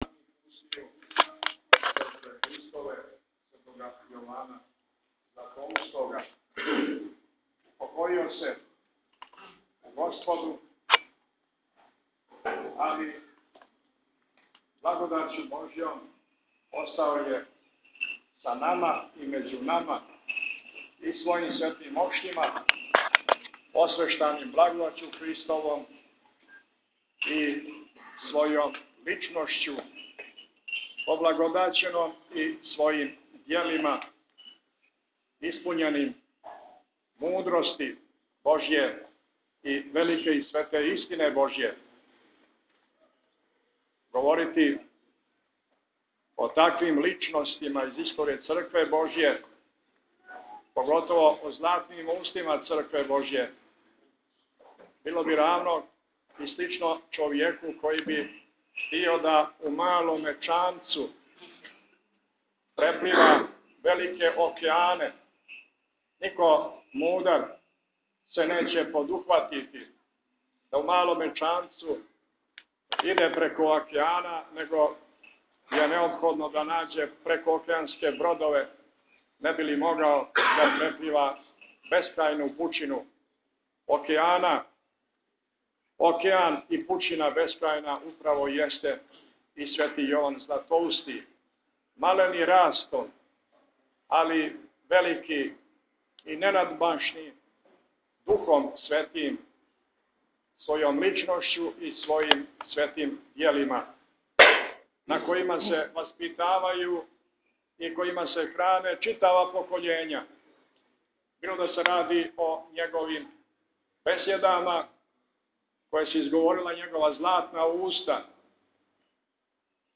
Предавање епископа Атанасија - Св. Јован Златоуст 1600 година